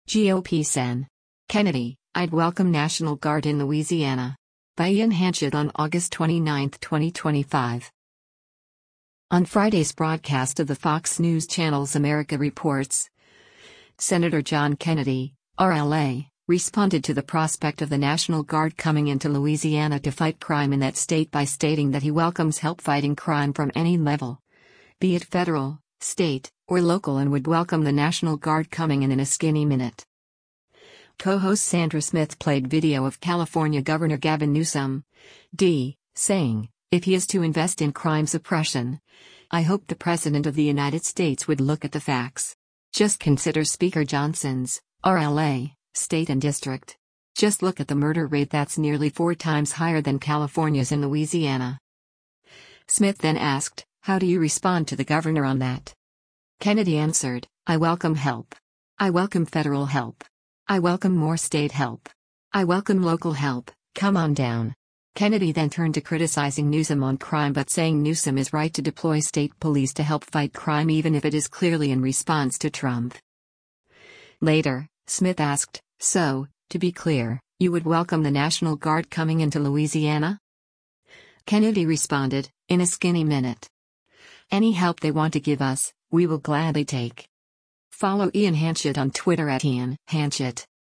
On Friday’s broadcast of the Fox News Channel’s “America Reports,” Sen. John Kennedy (R-LA) responded to the prospect of the National Guard coming in to Louisiana to fight crime in that state by stating that he welcomes help fighting crime from any level, be it federal, state, or local and would welcome the National Guard coming in “In a skinny minute.”
Co-host Sandra Smith played video of California Gov. Gavin Newsom (D) saying, “If he is to invest in crime suppression, I hope the president of the United States would look at the facts.